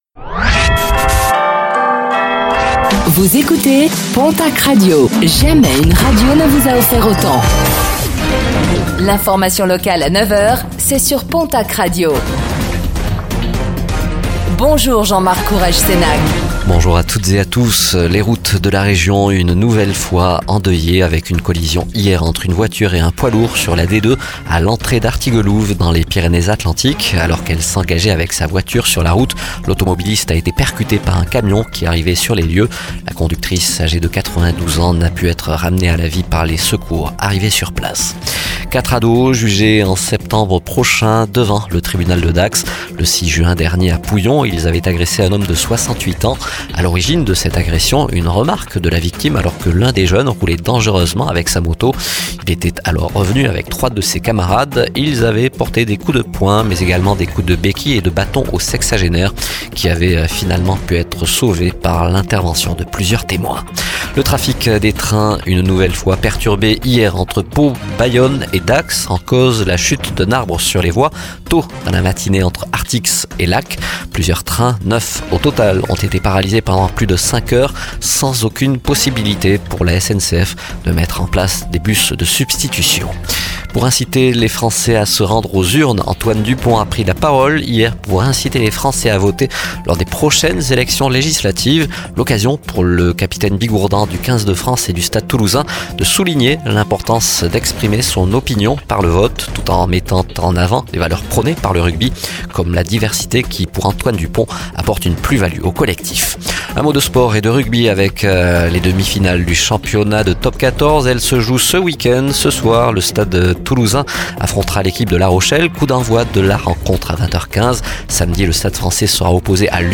Réécoutez le flash d'information locale de ce vendredi 21 juin 2024